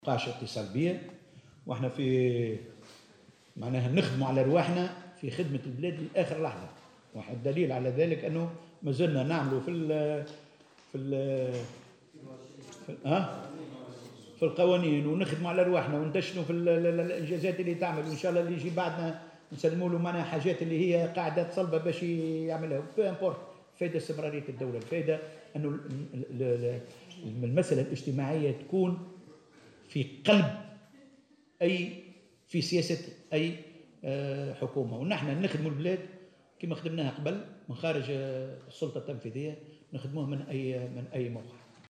وأضاف في تصريح اليوم على هامش ندوة صحفية عقدها بمقر الوزارة أنه سيعمل لصالح مصلحة تونس إلى آخر لحظة ومن أي موقع، و أن الاهم هو استمرارية الدولة، وفق قوله.